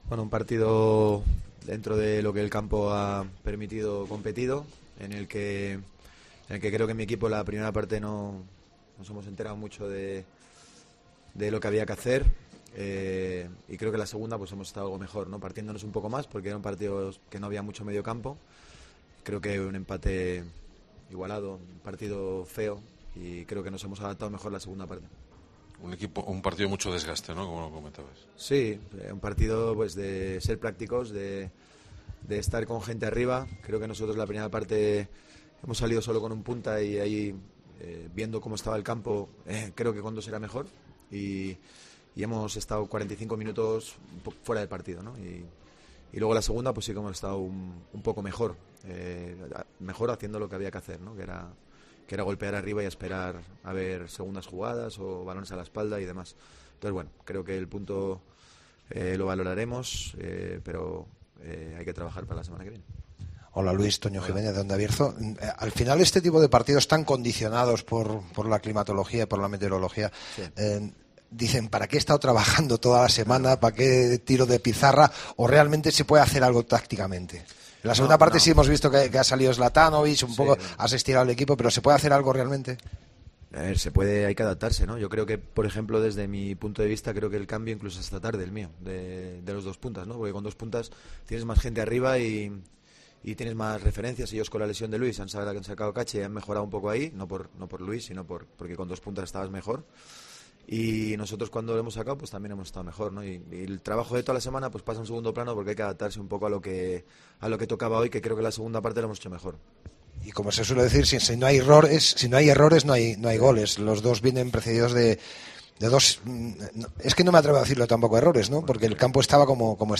POSTPARTIDO